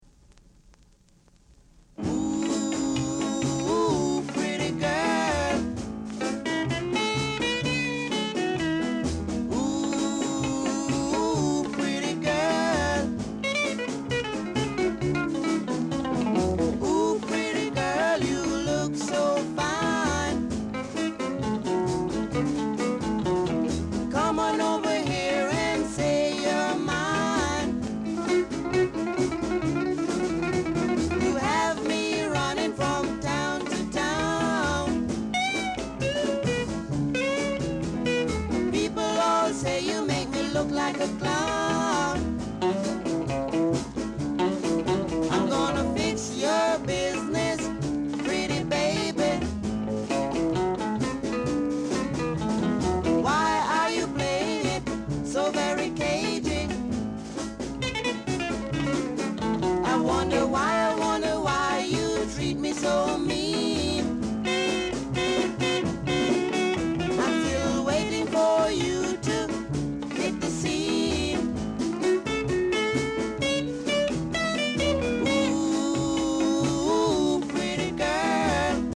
Doo Wop, R&B, Ska Male Vocal
Rare! nice Ja doo-wop ska vocal!